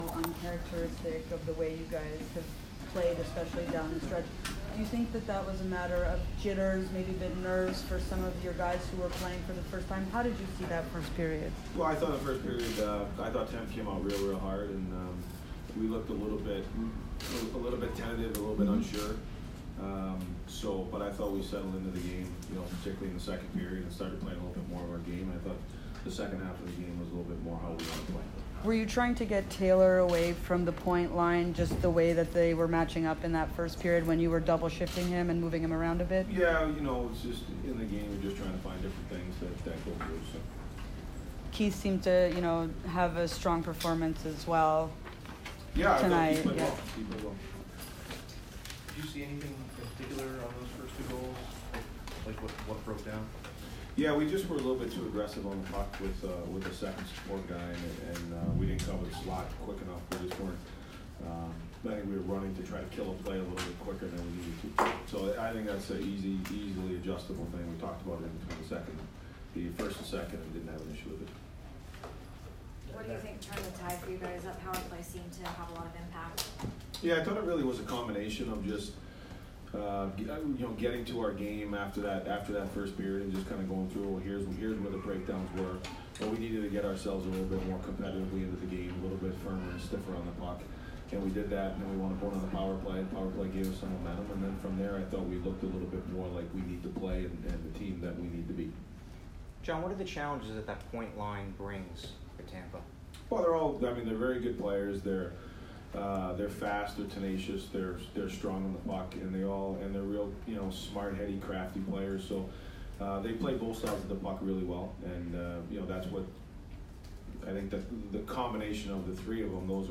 Devils head coach John Hynes post-game 4/12